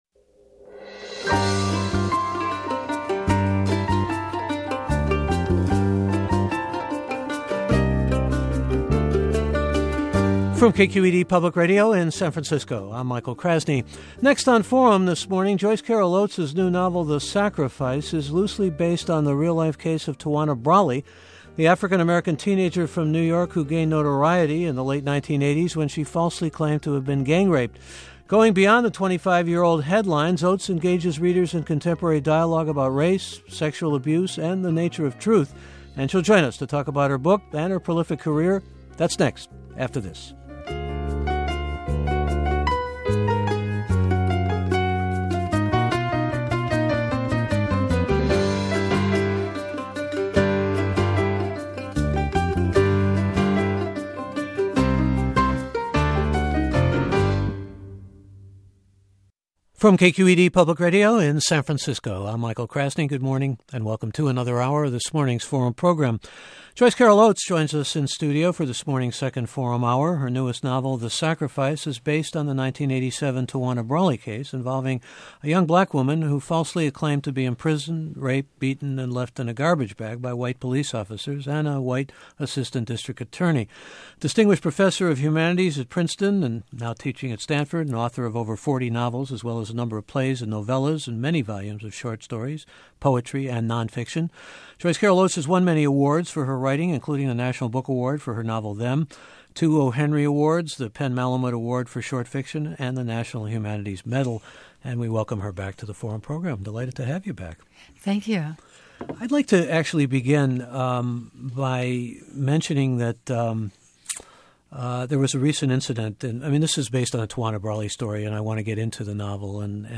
Michael Krasny interviews Joyce Carol Oates about her novel The Sacrifice for KQED’s Forum.